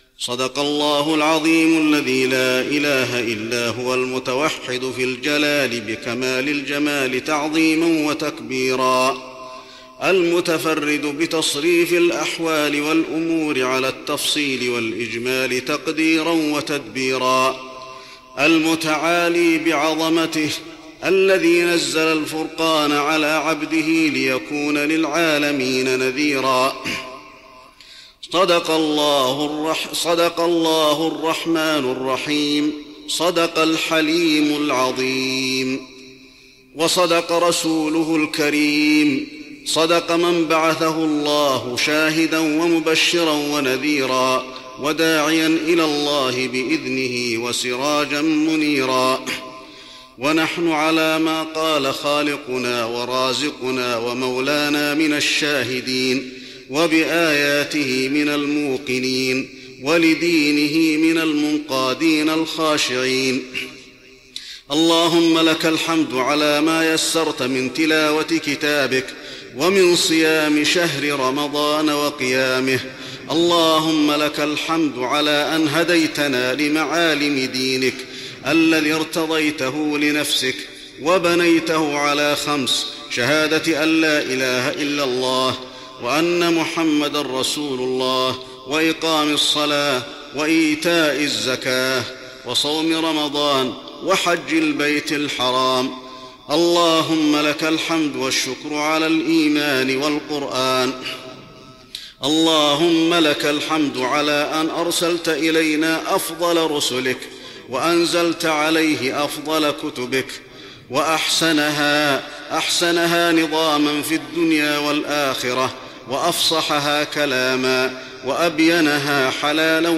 دعاء ختم القرآن رمضان 1415هـ > تراويح الحرم النبوي عام 1415 🕌 > التراويح - تلاوات الحرمين